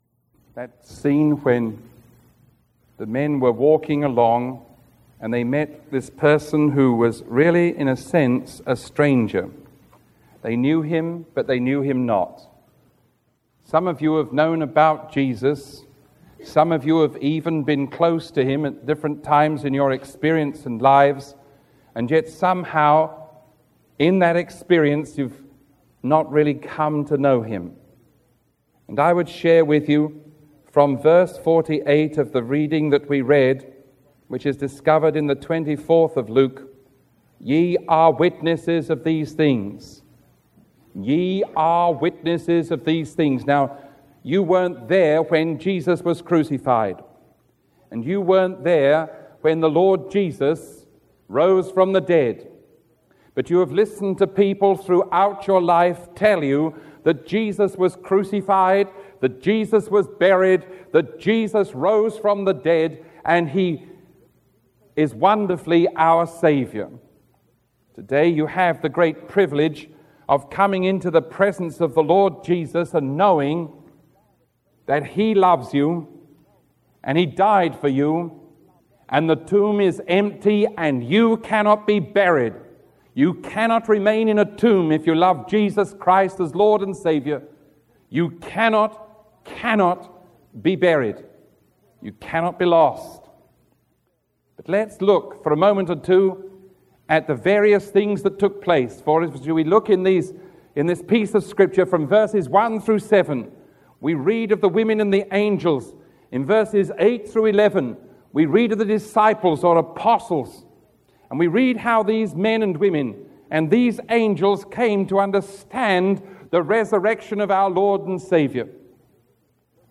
Sermon 0878A recorded on April 22